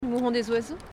uitspraak Mouron des oiseaux ↘ uitleg - En bas c’est beau, j’aime bien.